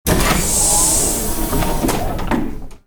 hissingdoorOpen.wav